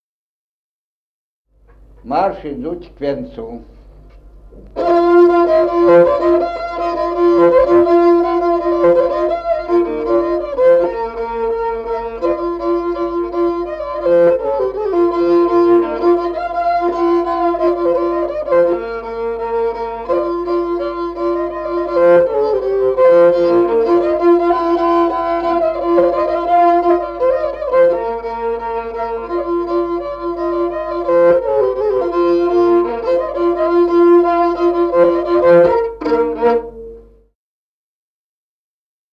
Музыкальный фольклор села Мишковка «Марш, идут к венцу», репертуар скрипача.